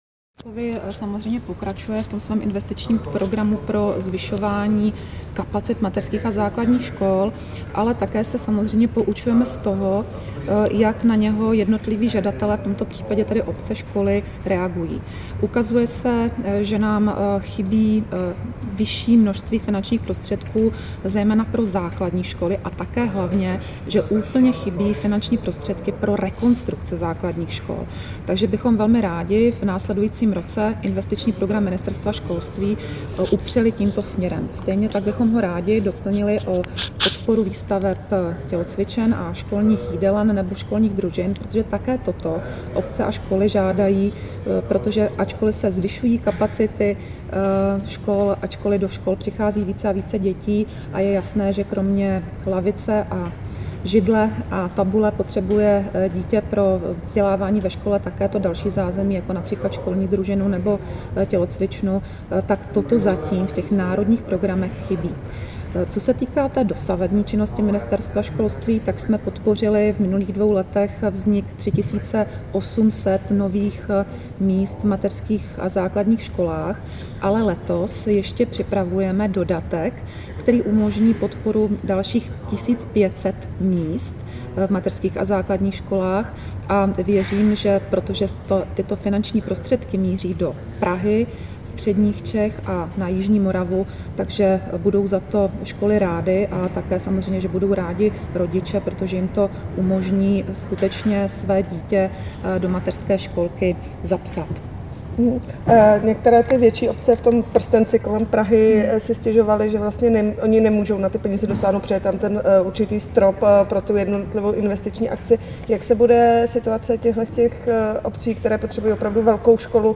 úvodní slovo ministryně Valachové.mp3